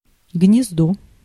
Ääntäminen
France: IPA: [yn pʁiz]